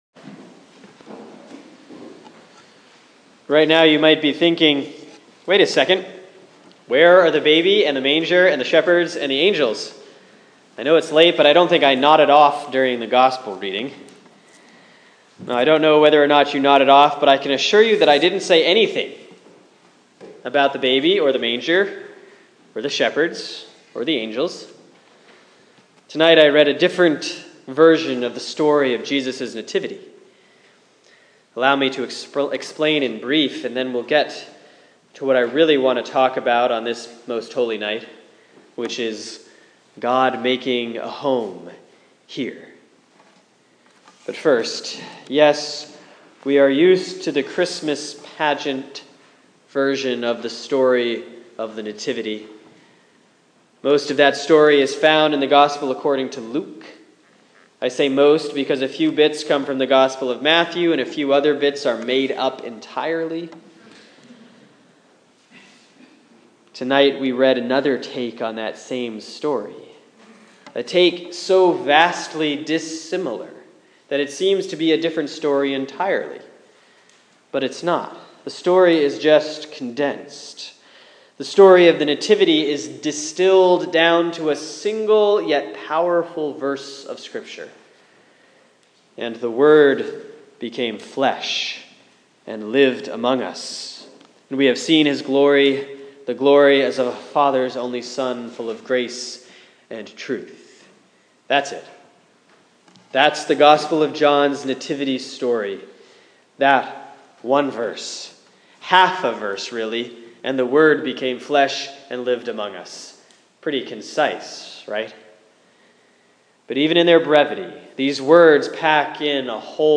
Sermon for Christmas Eve 2015; John 1:1-14